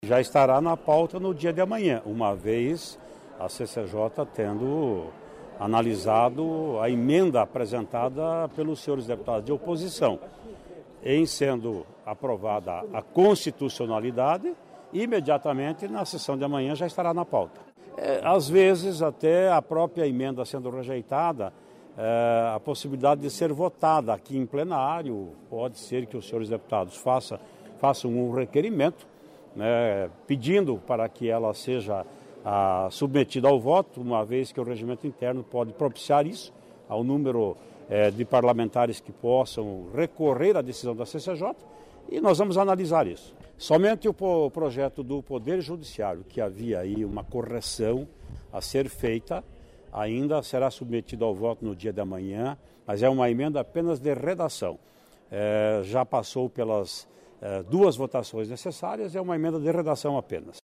O presidente da Assembleia Legislativa do Paraná, deputado Ademar Traiano, explicou as próximas etapas da tramitação dos projetos.